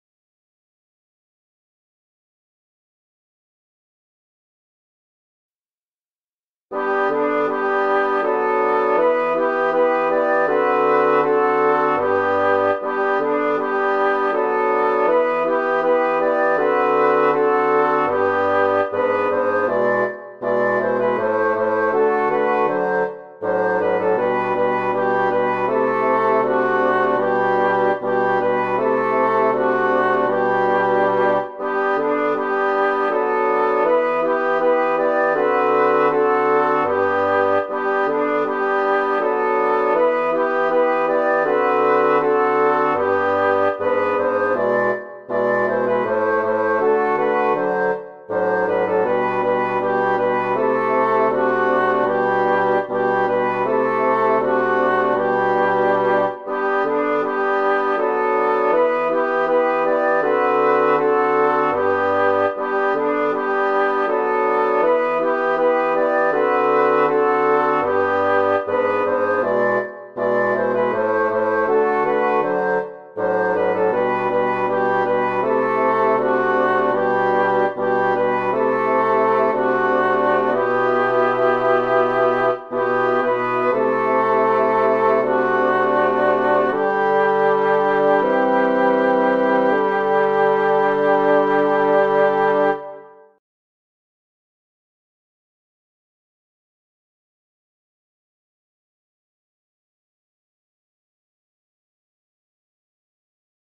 MP3 version instrumentale (les audios sont téléchargeables)
Toutes les voix